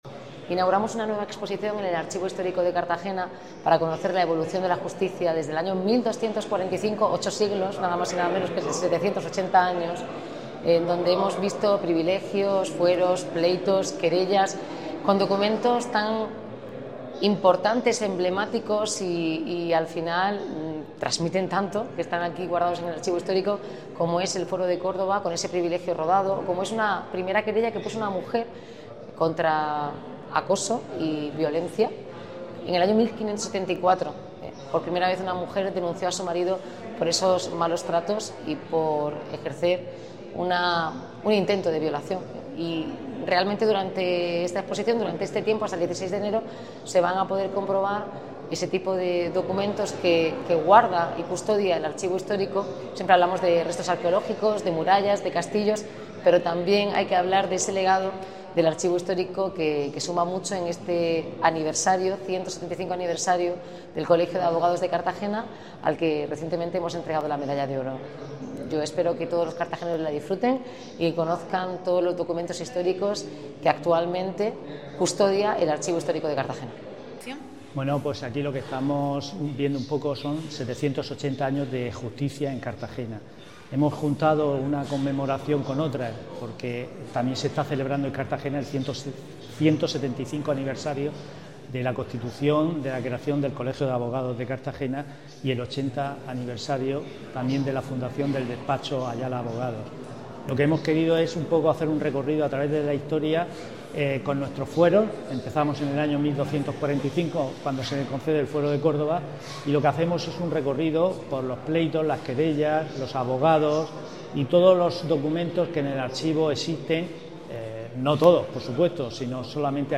Durante la inauguración, la alcaldesa de Cartagena, Noelia Arroyo, subrayó la vinculación histórica entre las primeras formas de justicia y los primeros gobiernos urbanos.